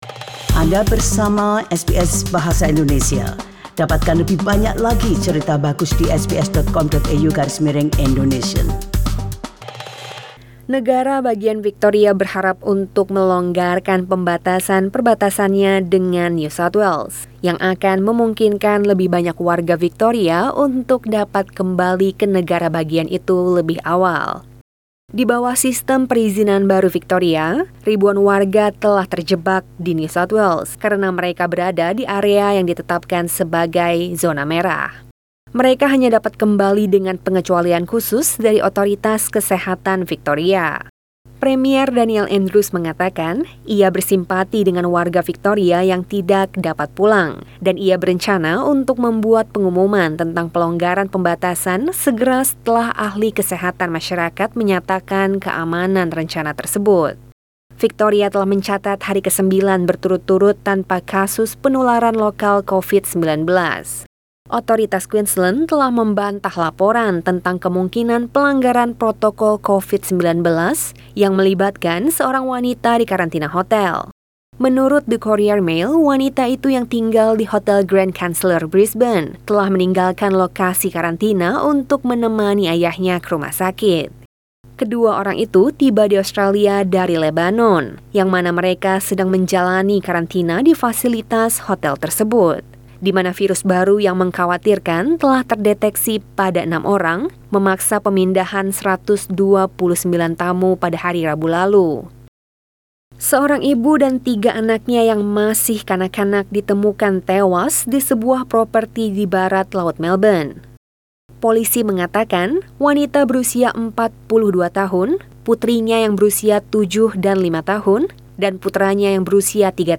Warta Berita SBS Radio dalam Bahasa Indonesia Source: SBS